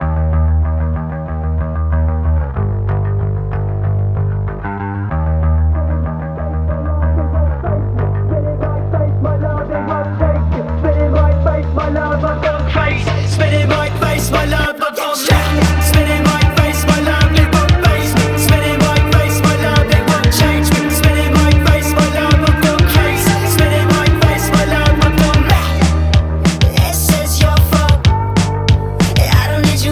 Жанр: Иностранный рок / Рок / Инди
# Indie Rock